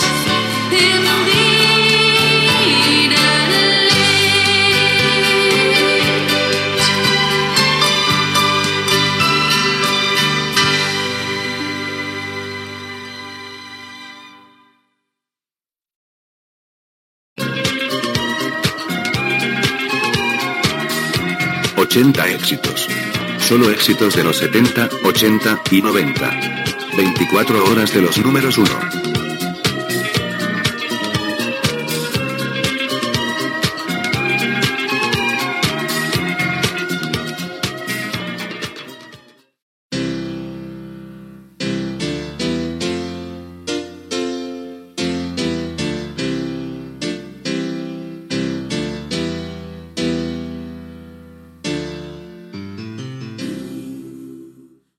Tema musical, identificació de la ràdio, tema musical